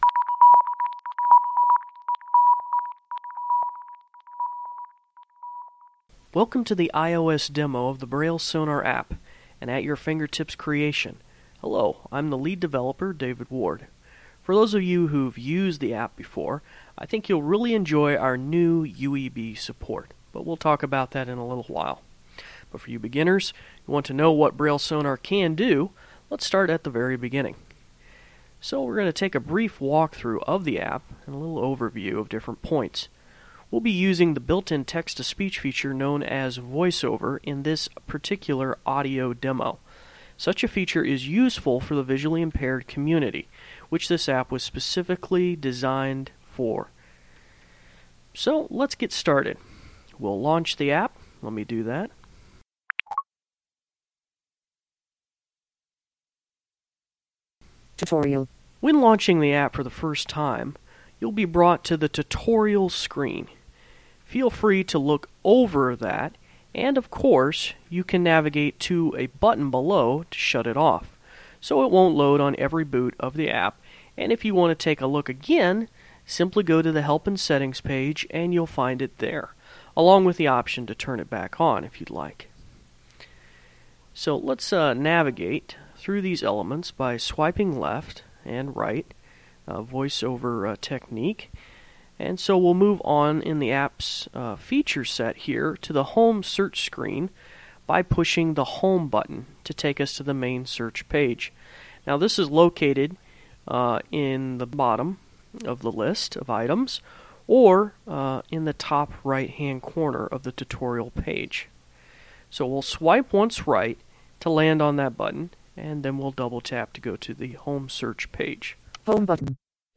voiceoverdemo.mp3